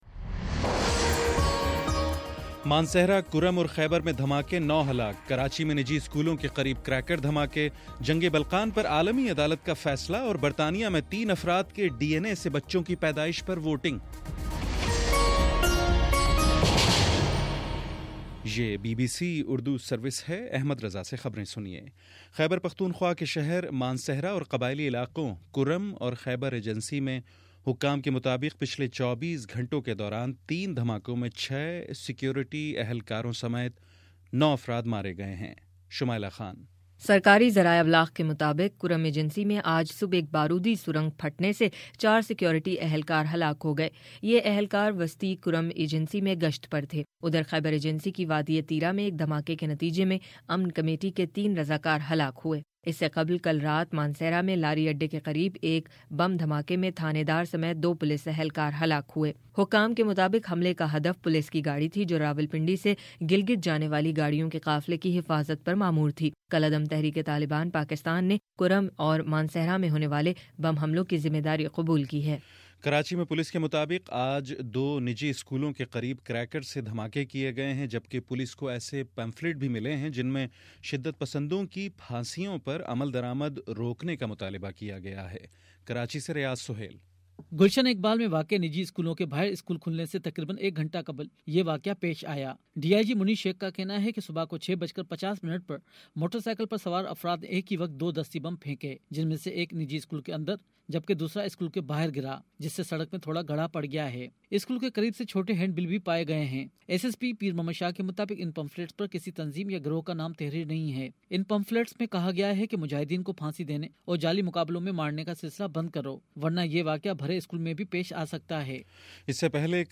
فروری 03: شام چھ بجے کا نیوز بُلیٹن